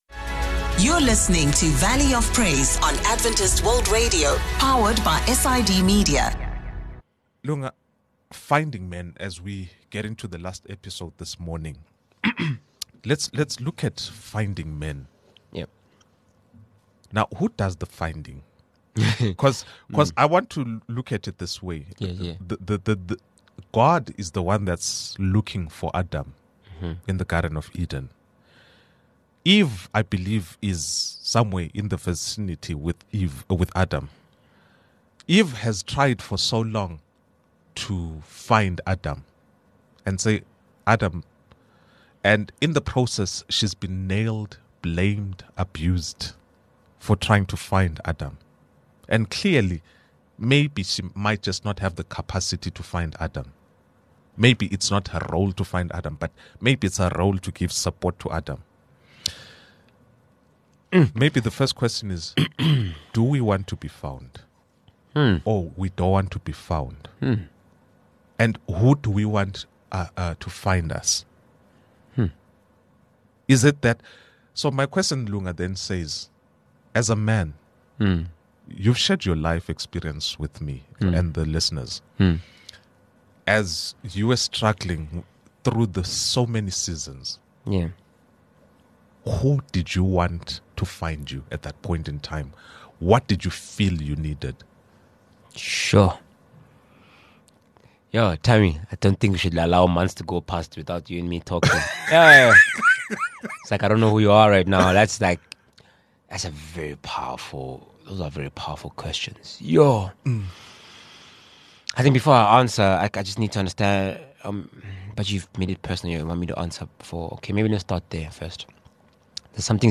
Discover how faith, vulnerability, and community can guide men to healing, purpose, and transformation. A powerful conversation on letting God lead the way!